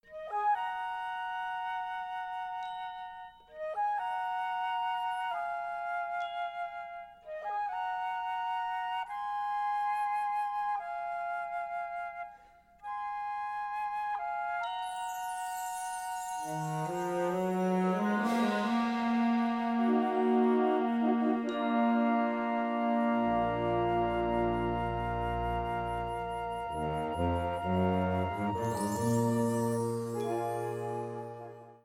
Catégorie Harmonie/Fanfare/Brass-band
Sous-catégorie Musique de concert
Instrumentation Ha (orchestre d'harmonie)
Mlt: Bells, Chimes
Xylophone